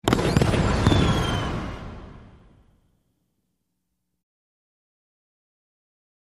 Explosions; Multiple 02